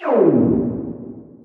fail.ogg